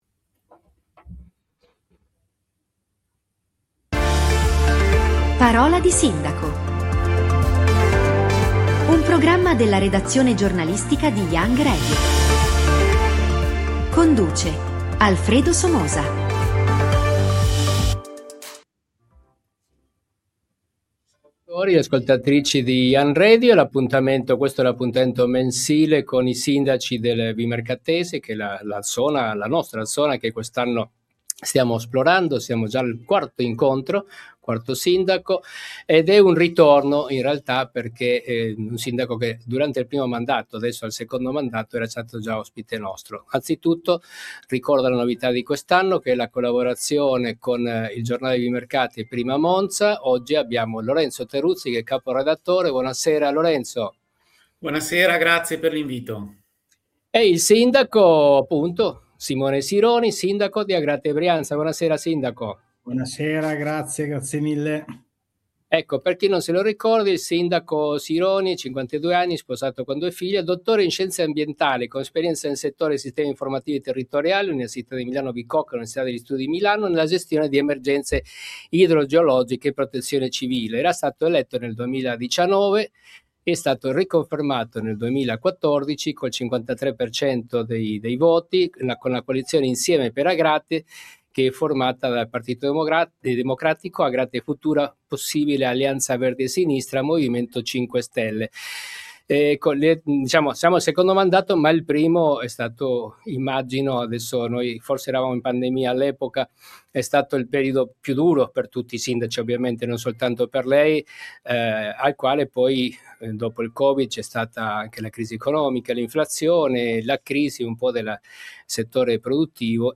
Parola di Sindaco è il format di Young Radio che racconta l'andamento delle città attraverso la voce dei loro amministratori